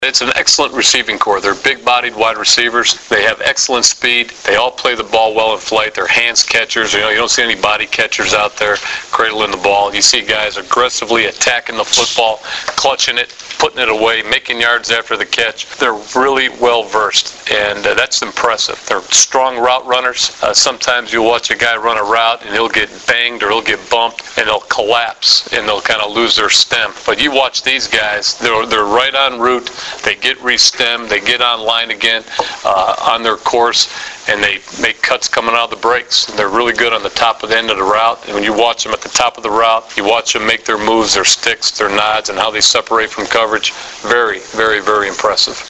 The following are audio links to press conference interview segments with Husker players and NU head coach Bill Callahan.